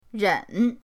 ren3.mp3